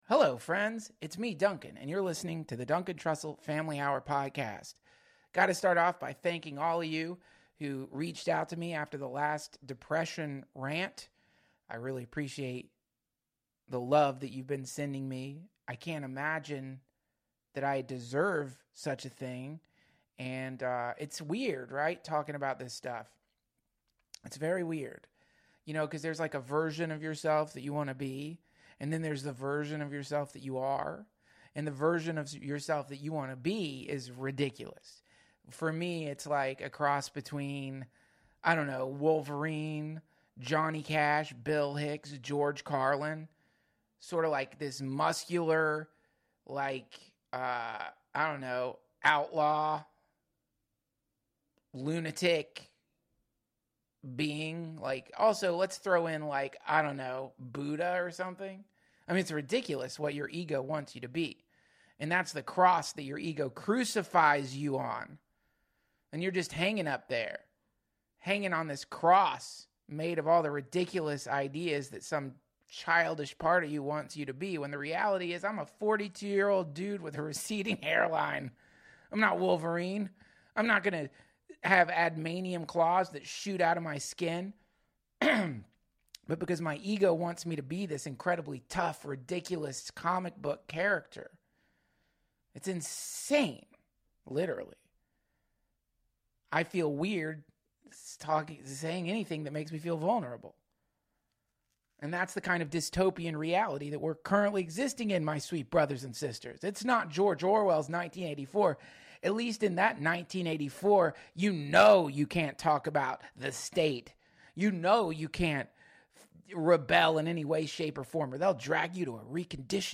Duncan tells a science fiction story about how a character in a dystopian reality used LSB to treat his lepression and is joined by the brilliant comedian Myq Kaplan who takes us on a guided ayahuasca simulation.